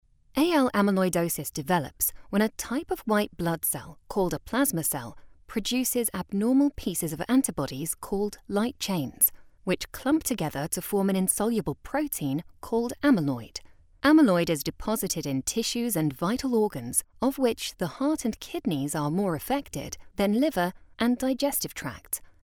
Inglés (Reino Unido)
Narración médica
Micrófono de condensador Neumann TLM 103
Cabina insonorizada con calidad de transmisión con paneles acústicos GIK